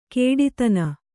♪ kēḍitana